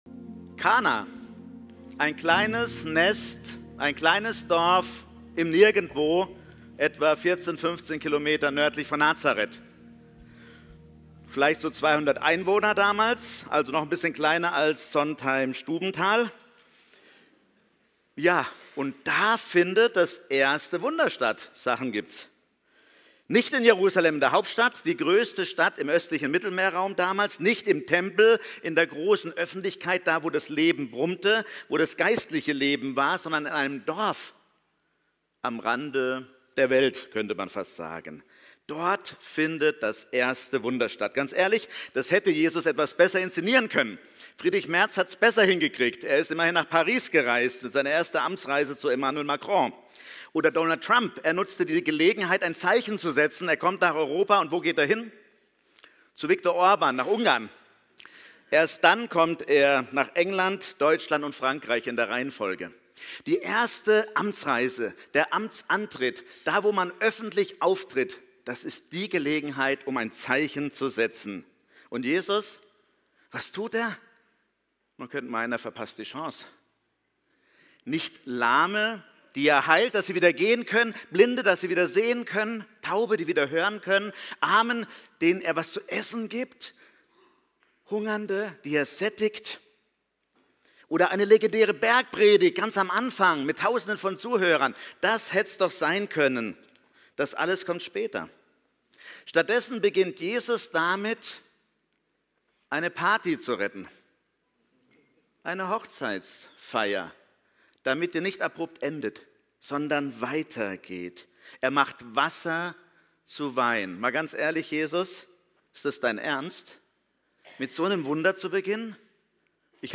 Typ: Predigt